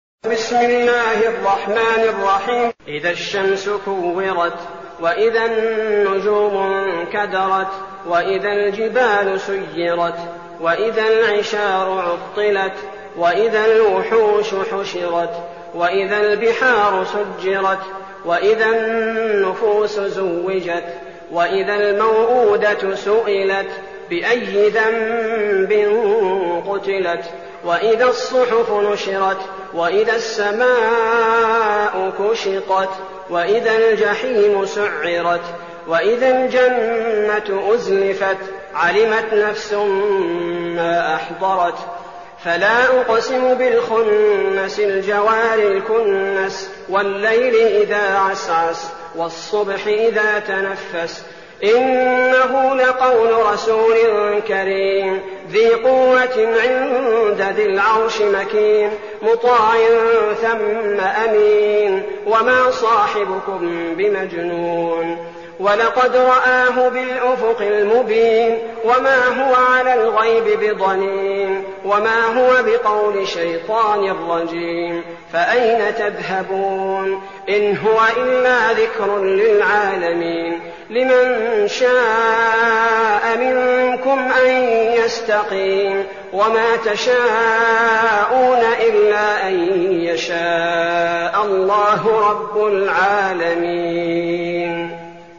المكان: المسجد النبوي الشيخ: فضيلة الشيخ عبدالباري الثبيتي فضيلة الشيخ عبدالباري الثبيتي التكوير